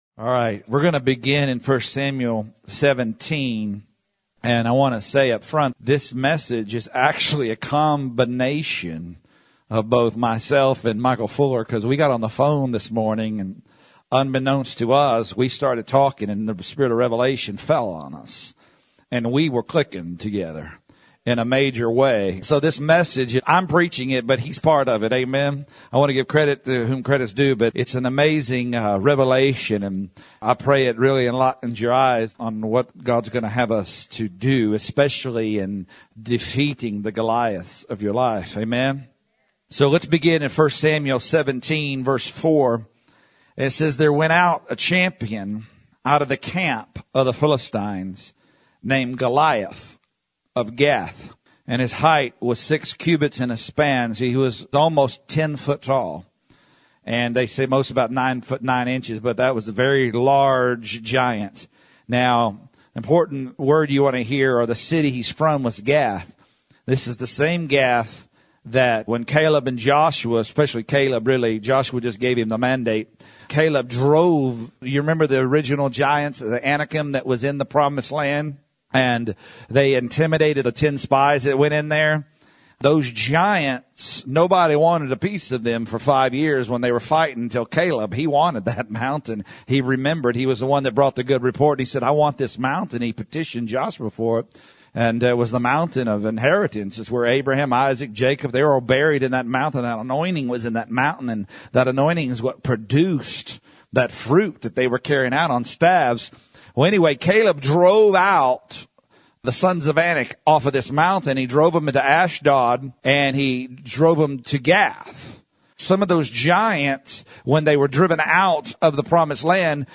Sermon about taking down the Goliaths in our lives and how it ties into the Right Hand of God